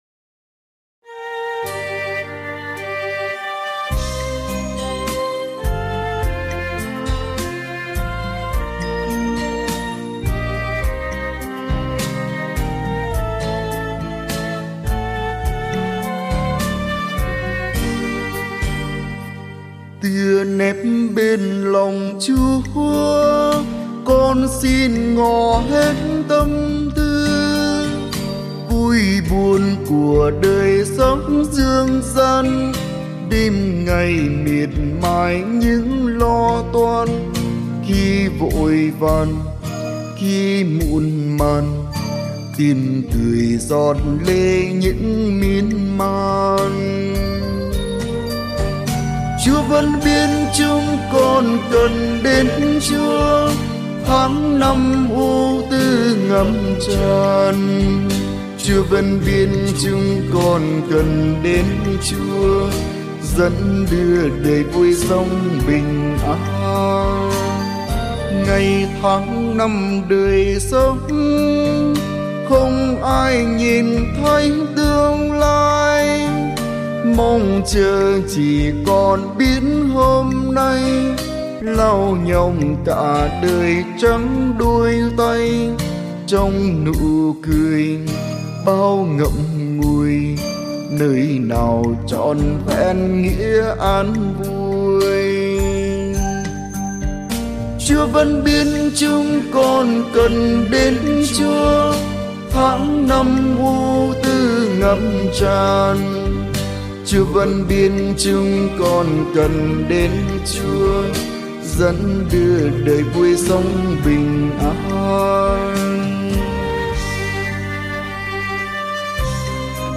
Thể loại: Ca Nguyện - Hiệp Lễ